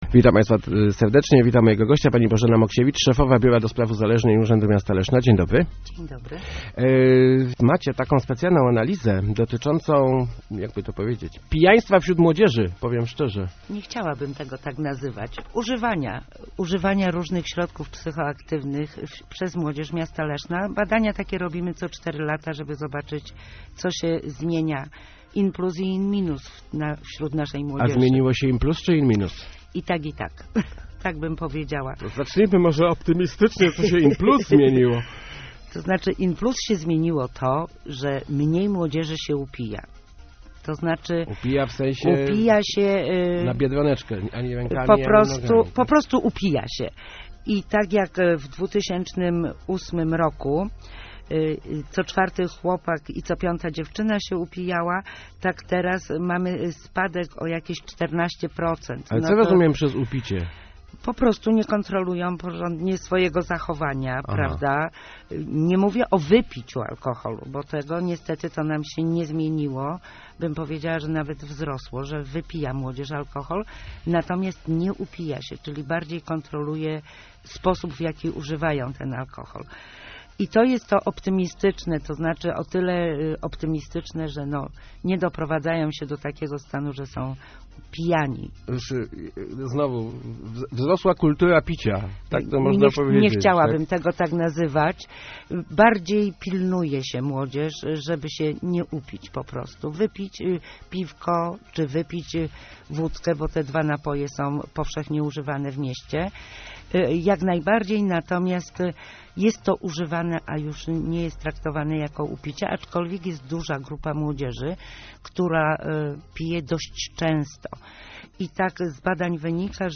Start arrow Rozmowy Elki arrow Młodzież coraz bardziej uzależniona